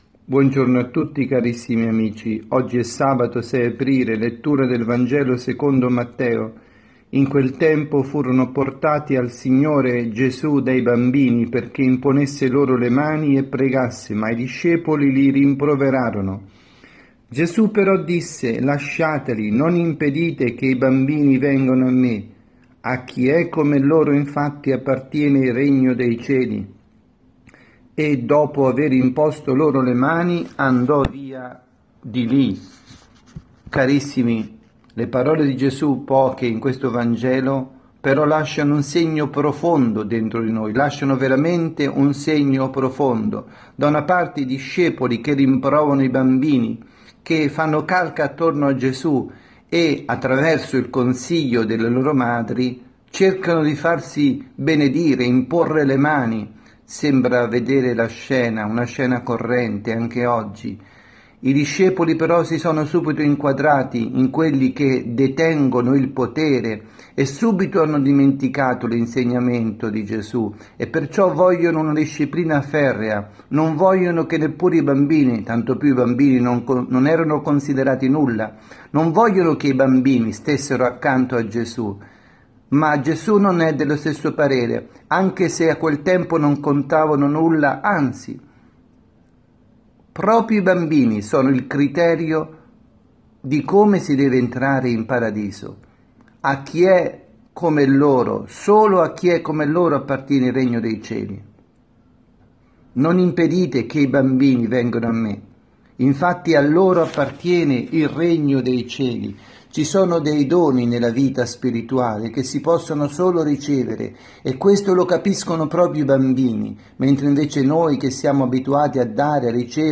Catechesi
dalla Parrocchia S. Rita – Milano